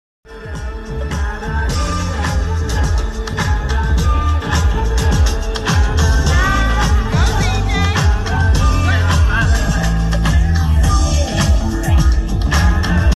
Can you believe its 3 songs playing?